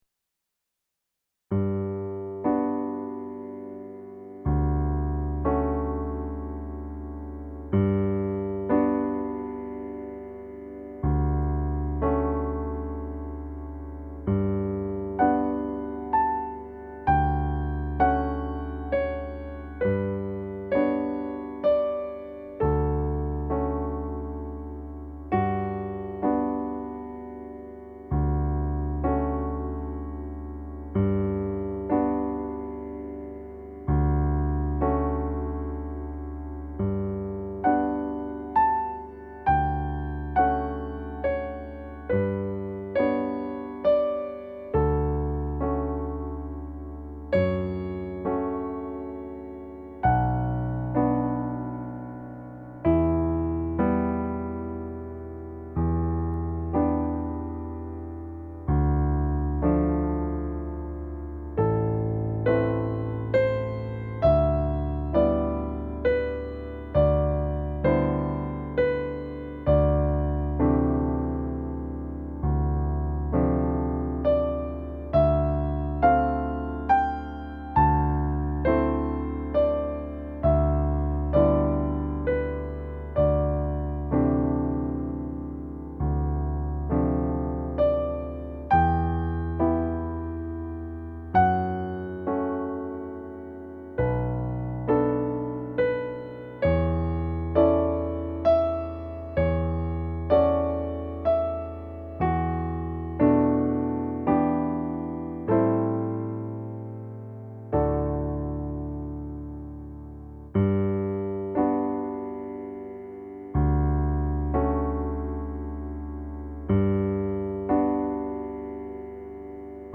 Mon piano : yamaha P140, C. Bechstein B210